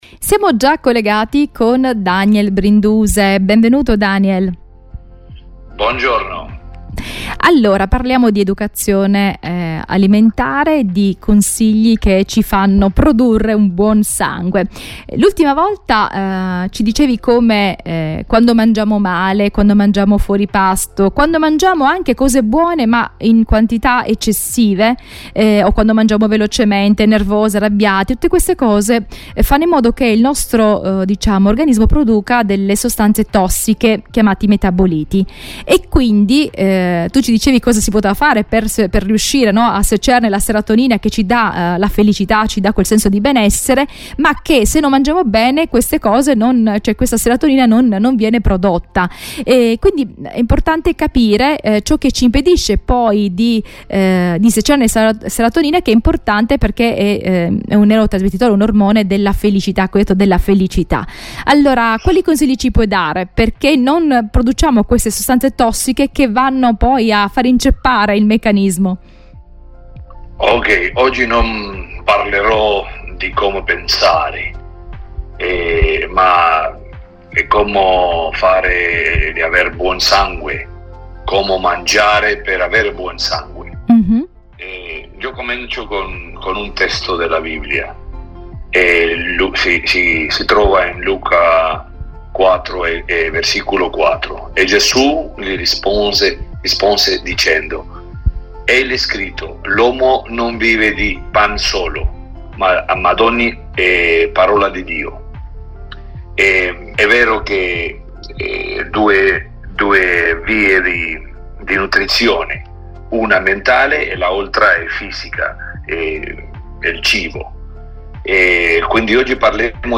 Una sana e accurata alimentazione produce un buon sangue, il processo inizia facendo una colazione completa di tutti i nutrienti necessari. Segui il dialogo